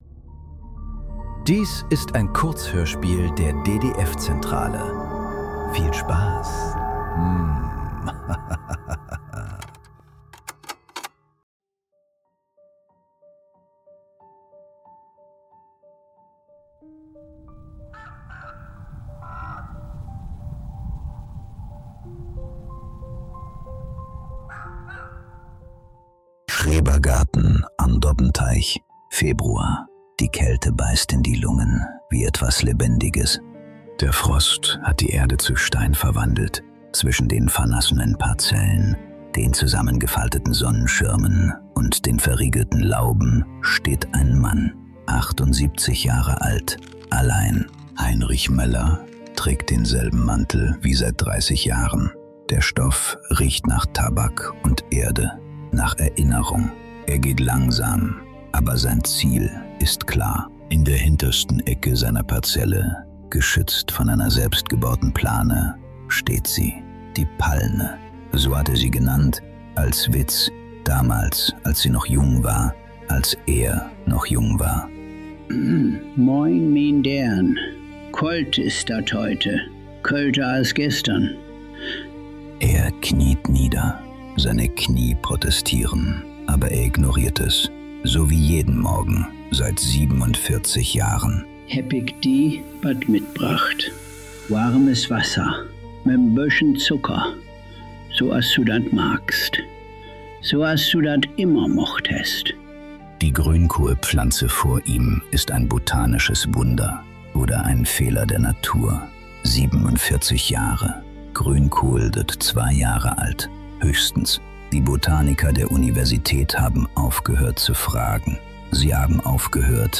Siebenundvierzig Winter ~ Nachklang. Kurzhörspiele. Leise.